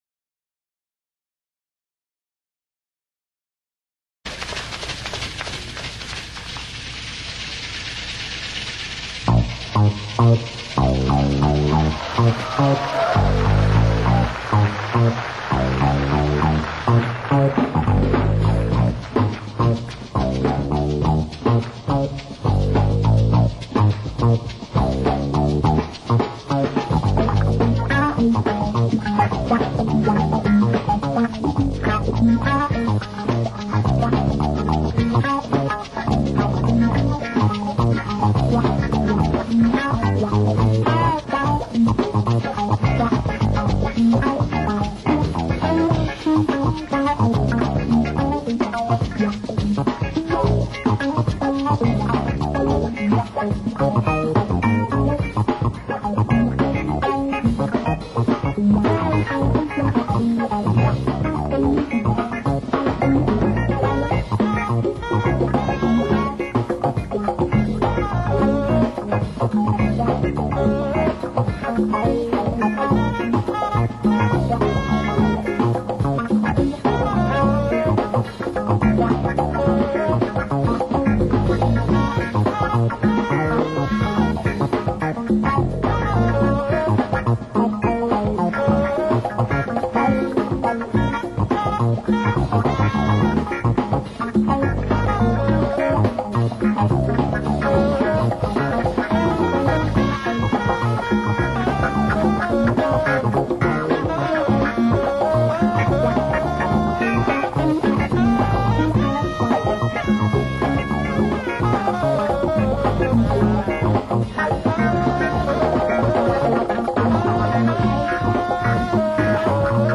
Le Jazz-Funk